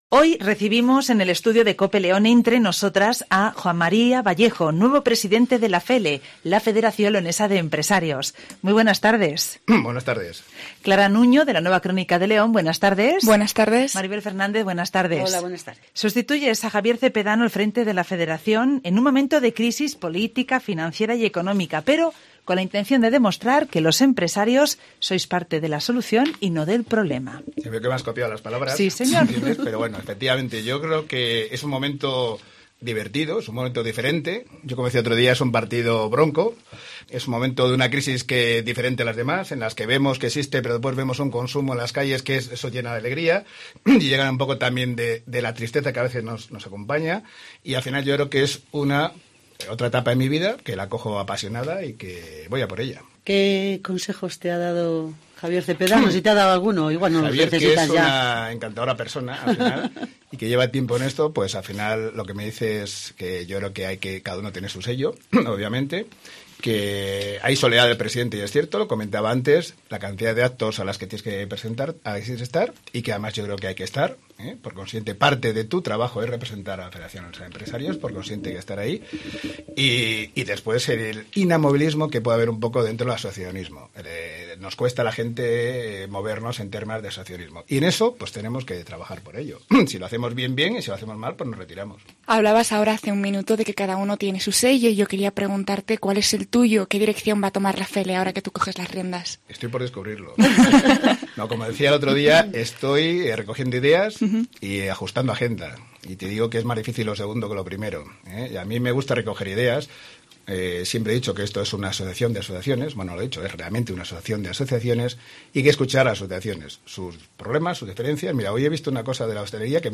Hoy recibimos en el estudio de Cope León “Entre Nosotras”